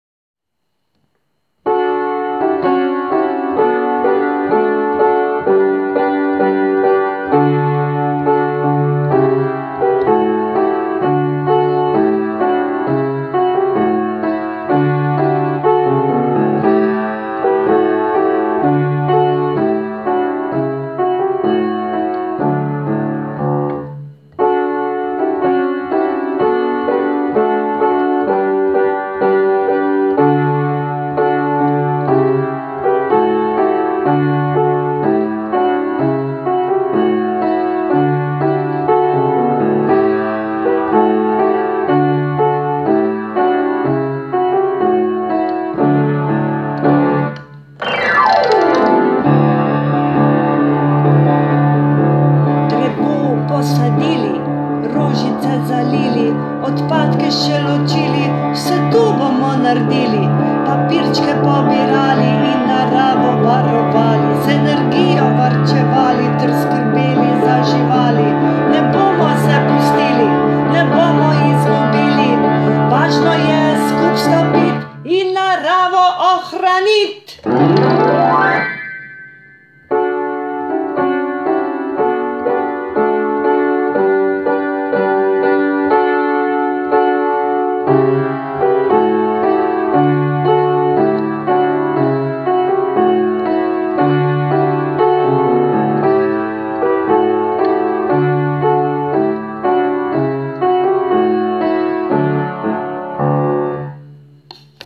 Potem pa pride na vrsto srednji del pesmi, ki ga je potrebno ”zarapati (zarepati)”. Ta del pesmi je v posnetku, ki ga prilagam tudi že zapet.
Posnetek pesmi: